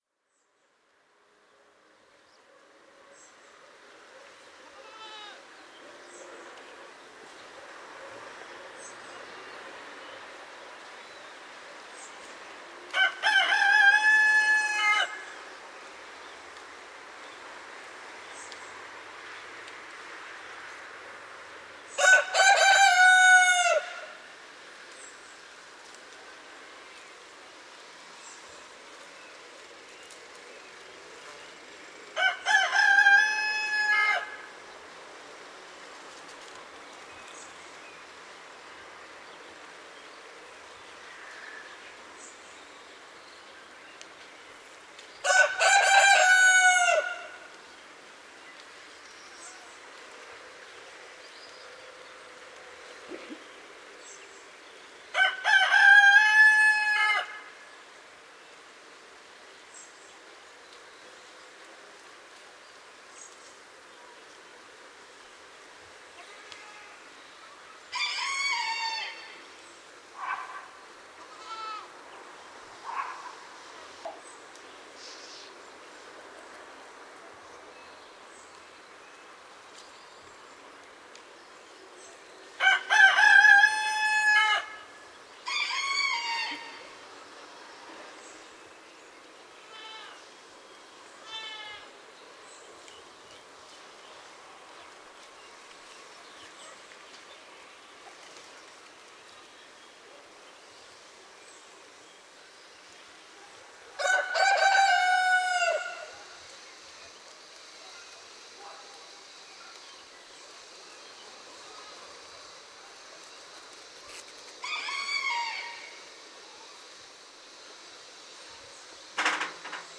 Gravação a partir de entre dois campos de forma a captar uma imagem estéreo adequada do que se ouvia em cada um deles. Por detrás do Largo do Calvário algumas galinhas, galos e cabras permaneciam em dois campos adjacentes. Gravado com Edirol R-44 e um microfone parabólico Tellinga PRO 7.
Santa-Cruz-da-Trapa-Galinhas-e-cabras.mp3